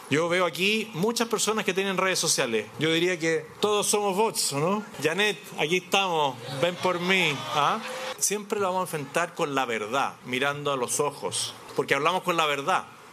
También, ayer, en una actividad en Talca abordó la situación con tono irónico.